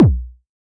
RDM_TapeB_SY1-Kick02.wav